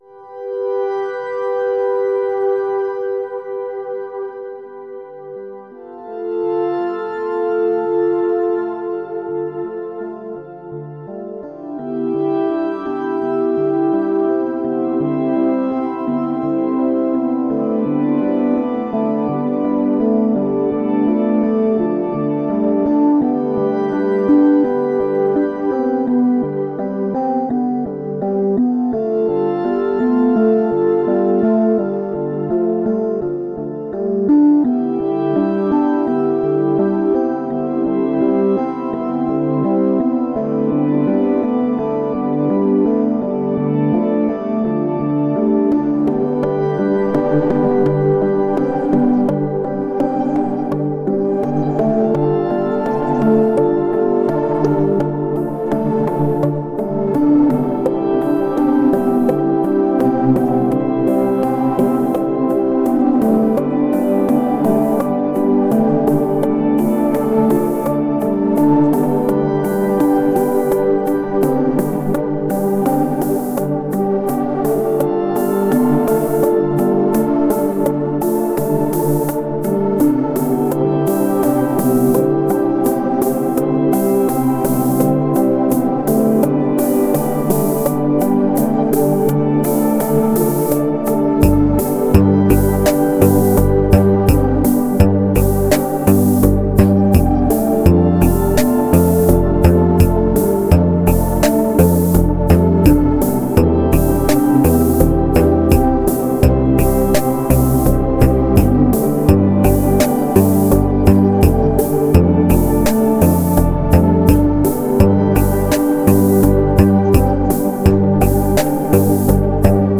Genre: Ambient, New Age.